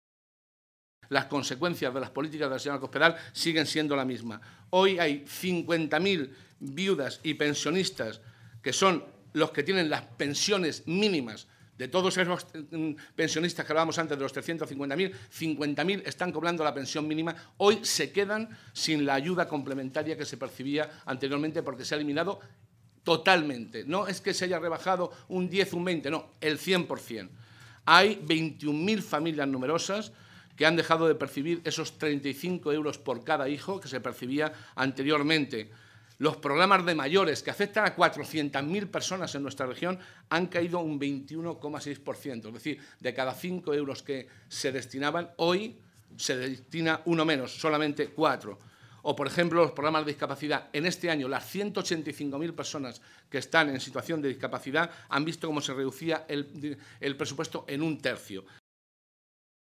José Molina, coordinador de Economía del Grupo Parlamentario Socialista
Cortes de audio de la rueda de prensa